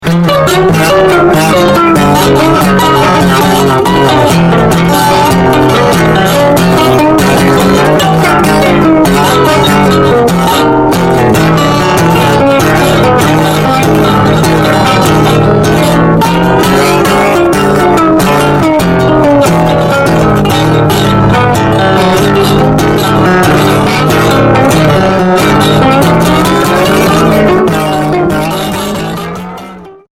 打楽器のごとく音と響きが乱れ飛びながら進んでいく様子が◎！
アコースティックギターのみで制作されたコチラの作品の続編が登場です！